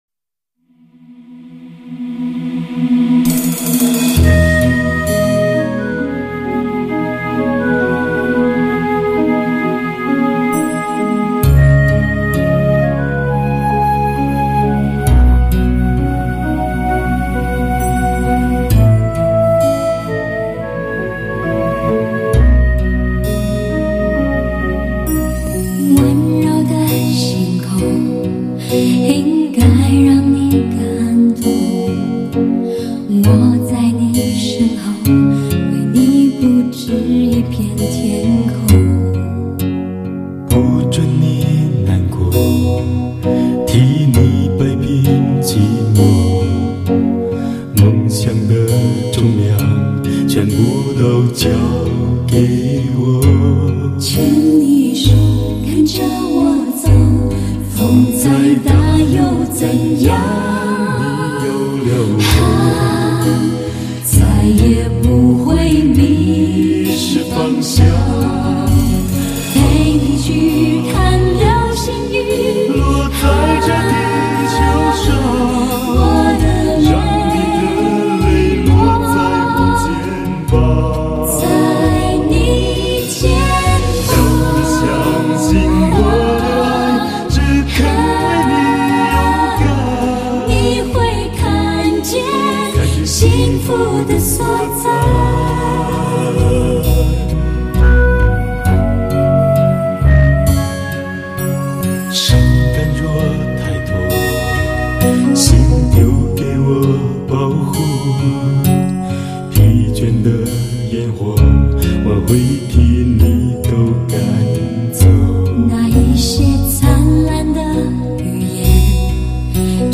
★ 真实的演绎，完美的录音，叙说出一段段动人的青春故事★
明朗而质朴，同时具有热情和技巧的演唱，充满活力，录音一流，更加令人心醉。